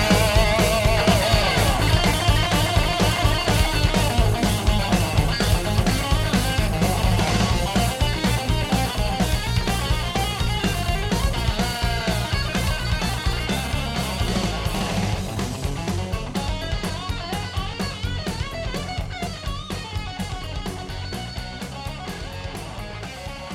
WALK IN SESSION